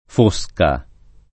Fosca [ f 1S ka ] pers. f.